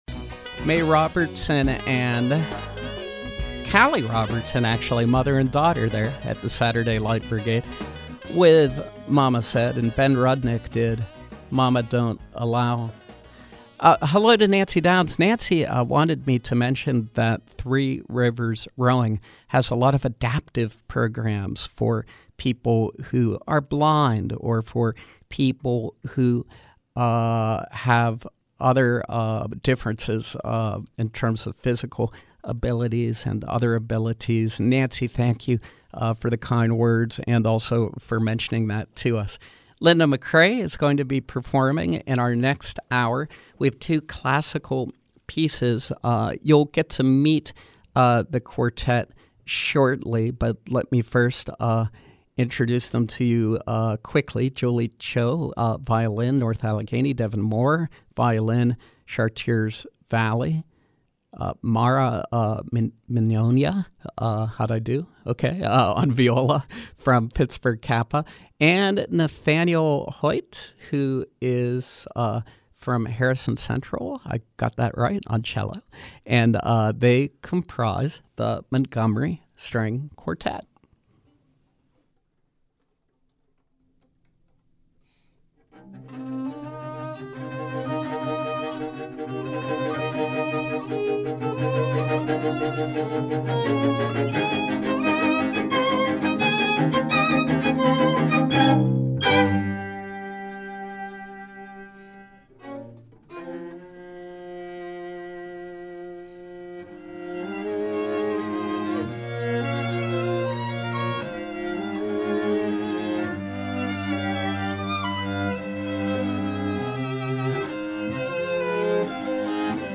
YES: Montgomery String Quartet